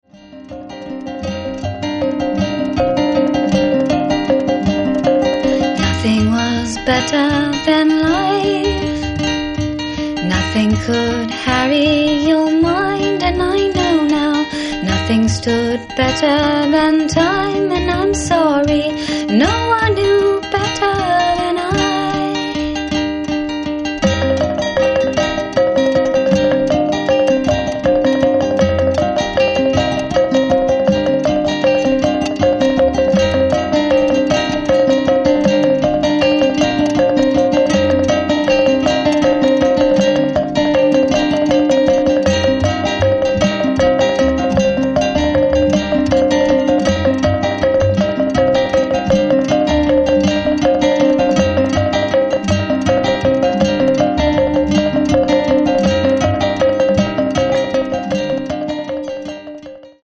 An acoustic folk gem